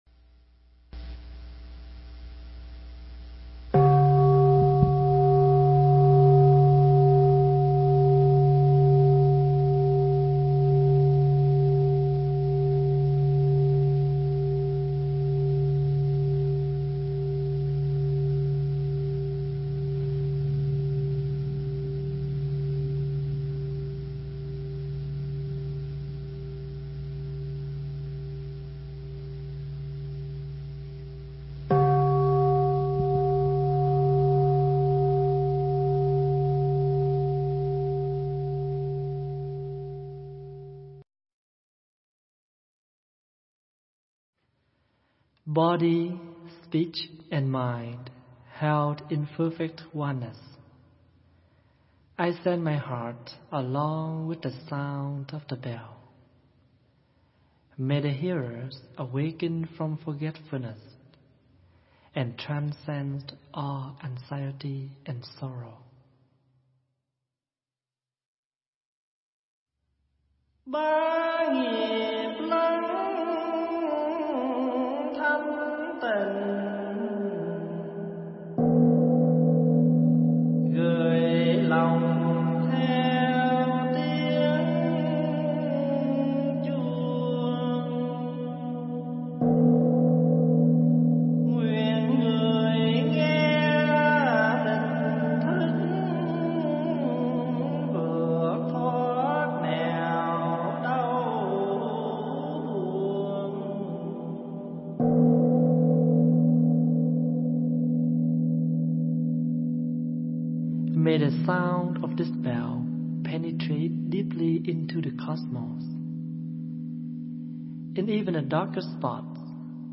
thuyết giảng tại Tu Viện Huyền Quang, Mỹ